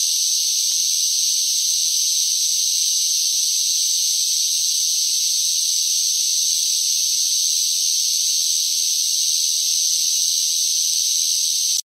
На этой странице собраны редкие записи, демонстрирующие разнообразие акустических сигналов этих многоножек: от шуршания ножками до стрекотания в моменты опасности.
Звуки сколопендры в дикой природе